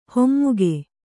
♪ hommuge